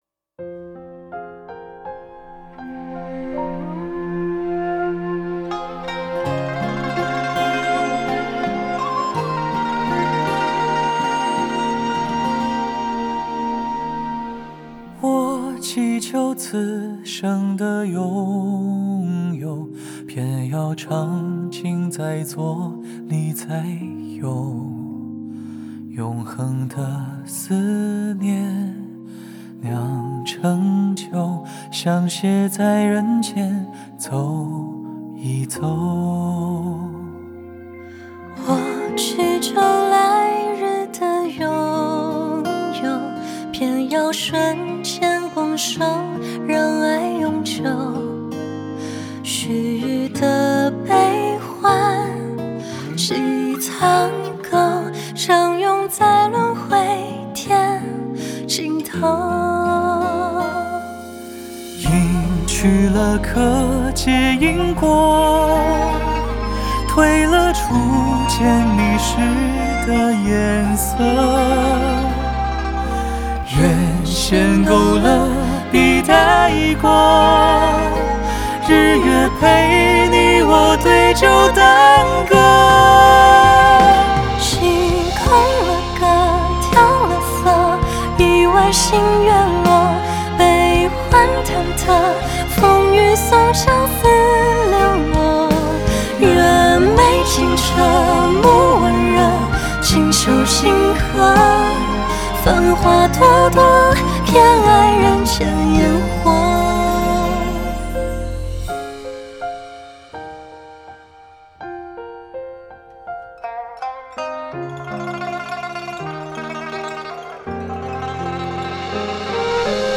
Ps：在线试听为压缩音质节选，体验无损音质请下载完整版
电视剧片尾曲